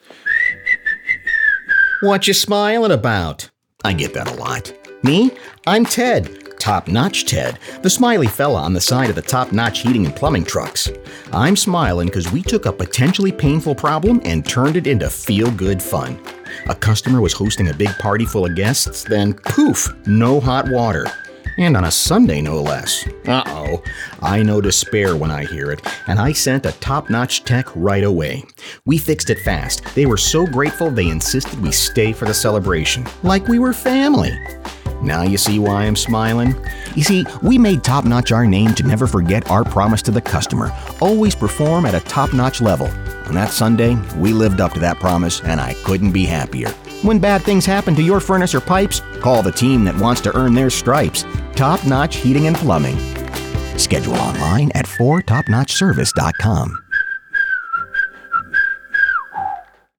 Radio Ad Introduction - Top Notch Heating and Plumbing